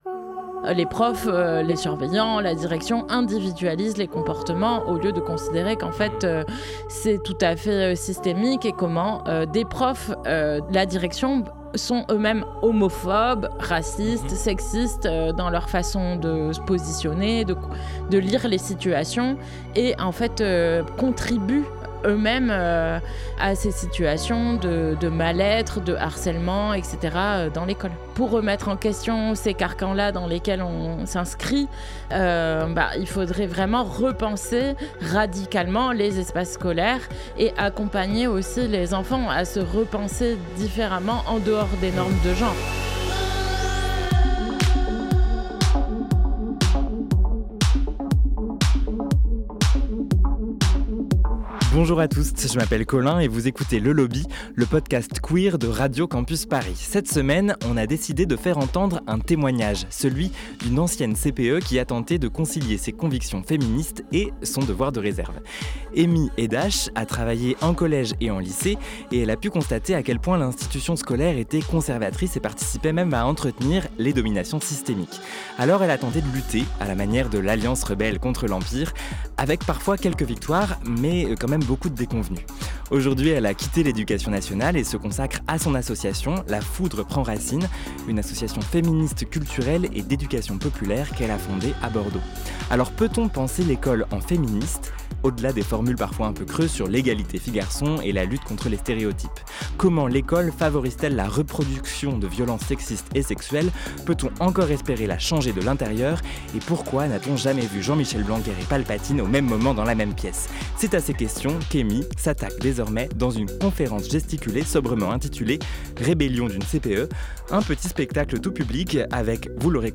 Partager Type Magazine Société vendredi 8 novembre 2024 Lire Pause Télécharger À l'occasion de la semaine du harcèlement scolaire